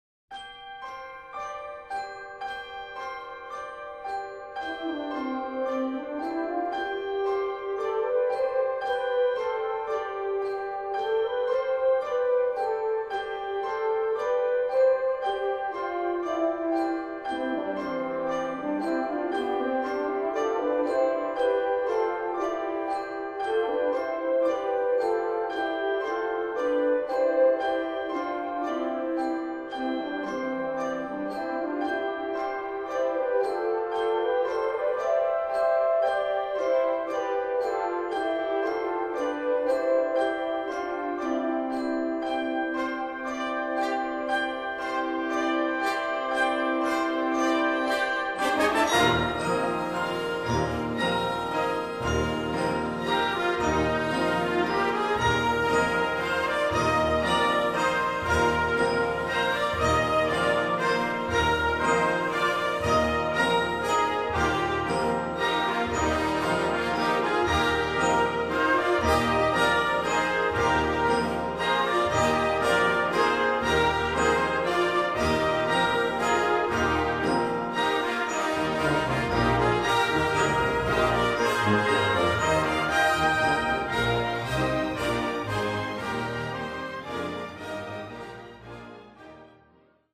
傳統的聖誕音樂
The first Noel（聖誕佳音）（十八世紀英國頌歌）